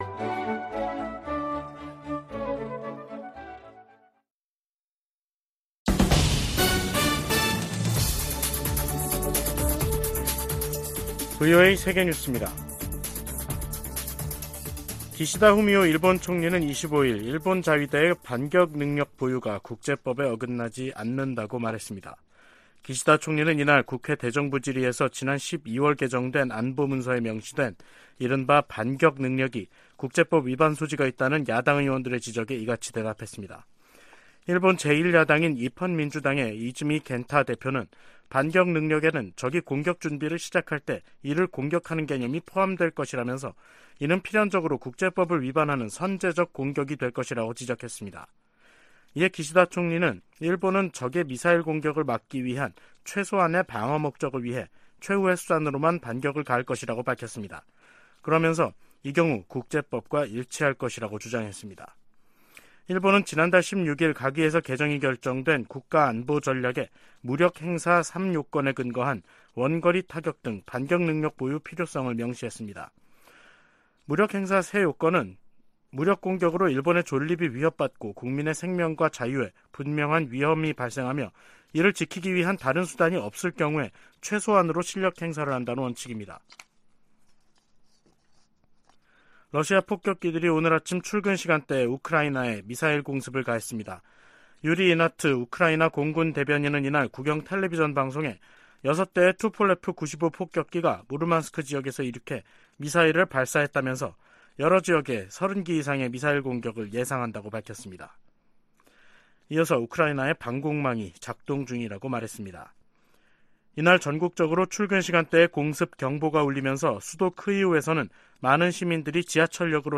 VOA 한국어 간판 뉴스 프로그램 '뉴스 투데이', 2023년 1월 26일 3부 방송입니다. 유엔군사령부는 한국 영공에 무인기를 침투시킨 북한과 이에 대응해 북한에 무인기를 날려보낸 한국 모두 정전협정을 위반했다고 평가했습니다. 유엔인권기구와 유럽연합이 미국 정부의 북한인권특사 지명을 환영했습니다.